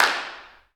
Claps
OAK_clap_mpc_08.wav